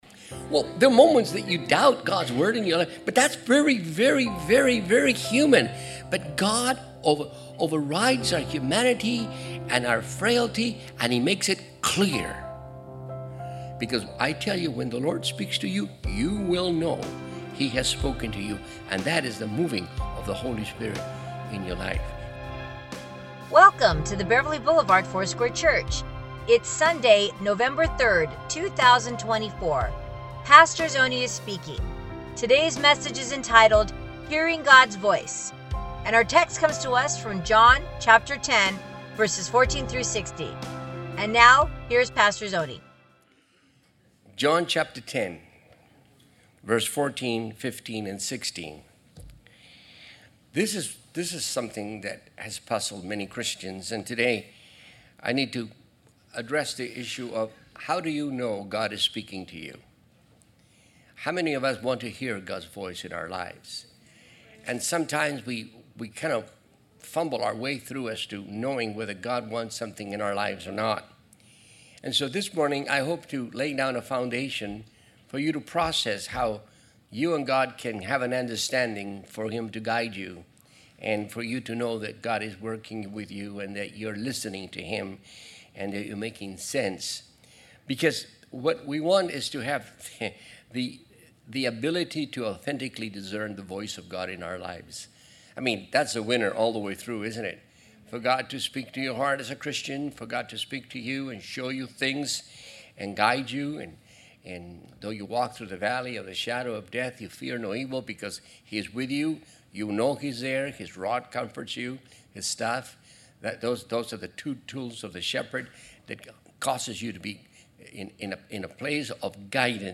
Sermons | Beverly Boulevard Foursquare Church